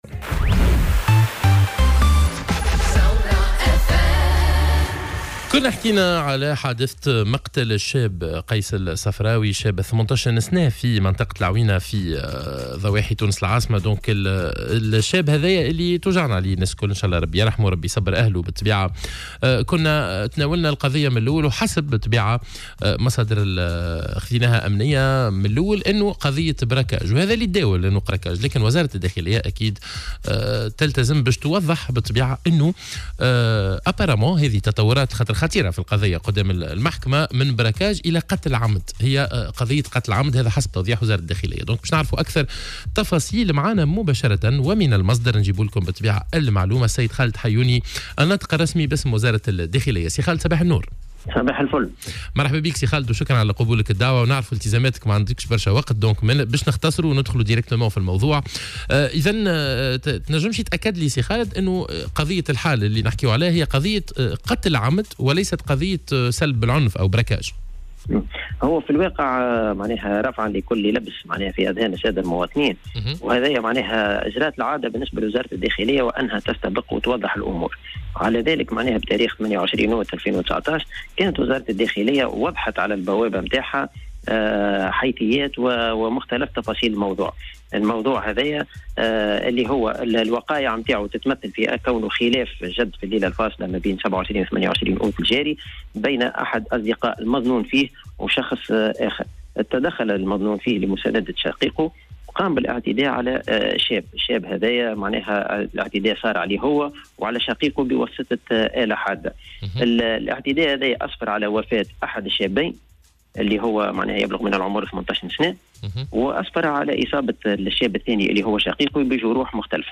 Dans son intervention dans l'émission Sbeh El Ward de ce vendredi 30 août 2019